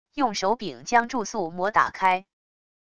用手柄将注塑模打开wav音频